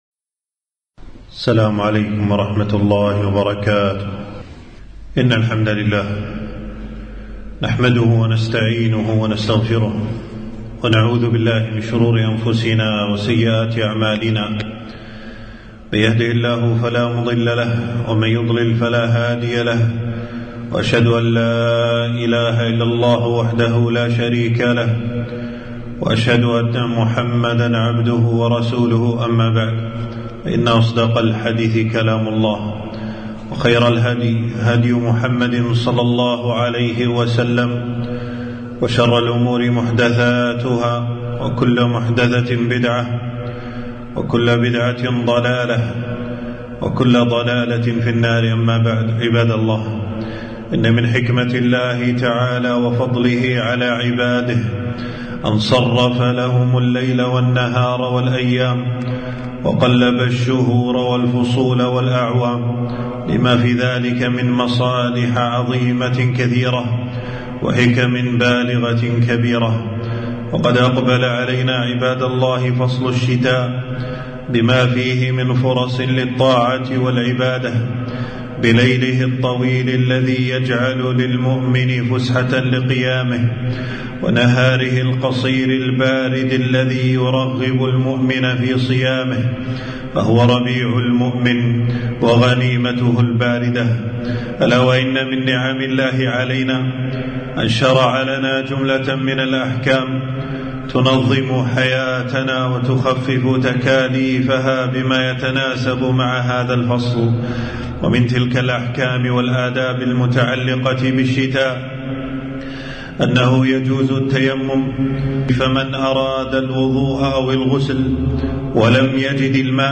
خطبة - أحكام يحتاجها المسلم في الشتاء